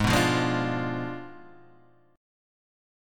G#dim7 chord